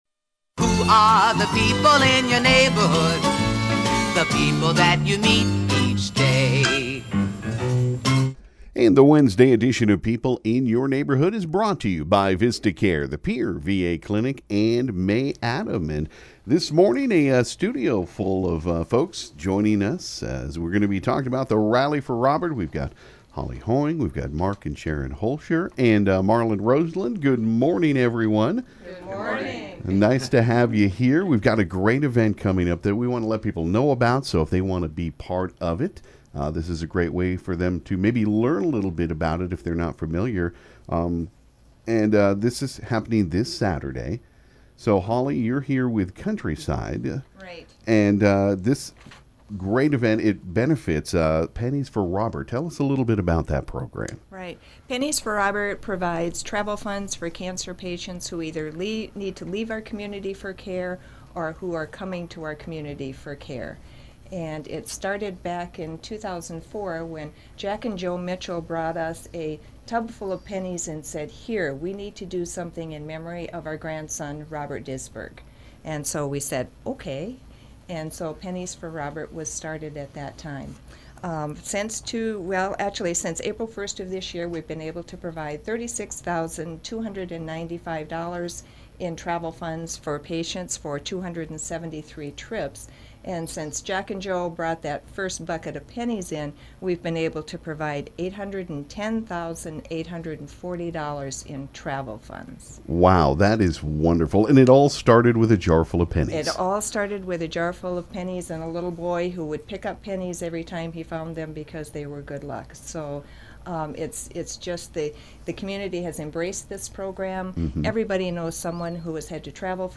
This morning the KGFX studio was full